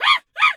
monkey_2_chatter_scream_03.wav